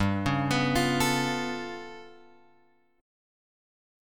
G 9th Flat 5th